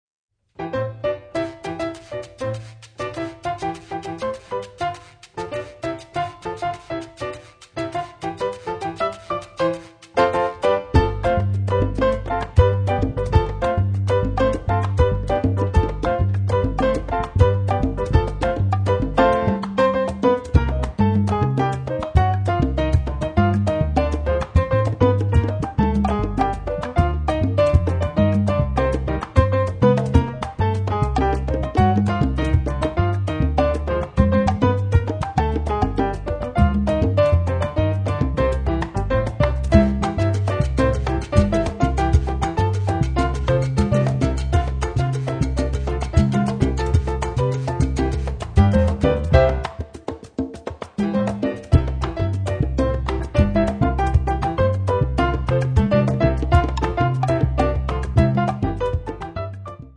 piano
percussion tr. 4
in stile cubano e il Prélude